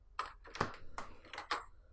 描述：重金属鼓......在吉他专业中制造。
Tag: 120 bpm Heavy Metal Loops Drum Loops 690.13 KB wav Key : Unknown